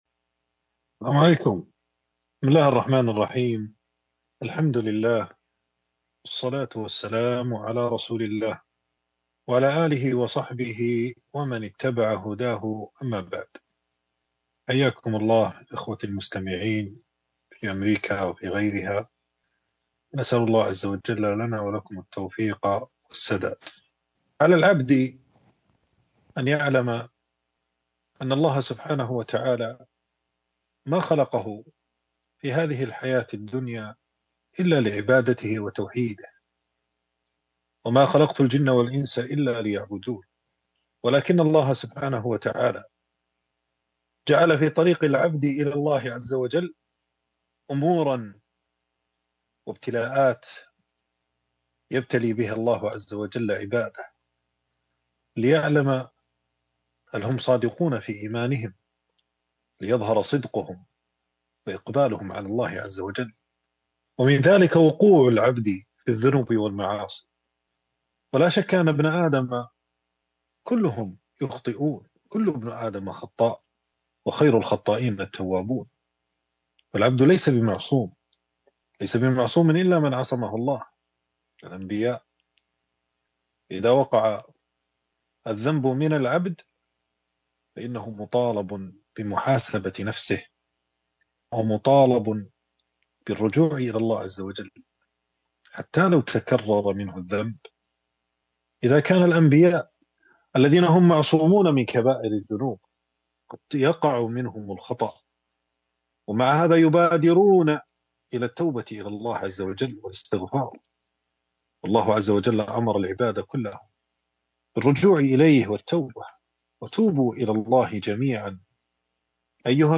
خيرُ الخطائين التوابون (مترجمة) الثلاثاء, ديسمبر , 2025 | 618 | طباعة الصفحة تنزيل تنزيل التفريغ محاضرة مترجمة بعنوان: خيرُ الخطائين التوابون.